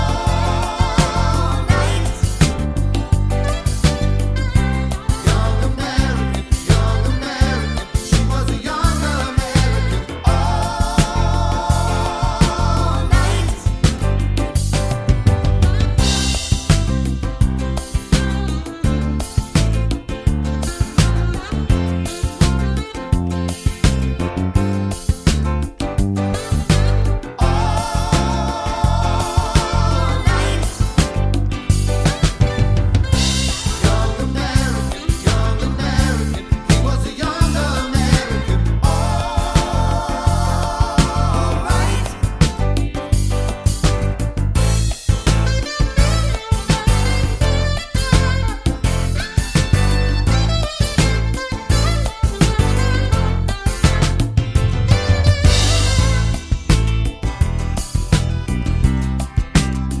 no sax Pop (1980s) 3:15 Buy £1.50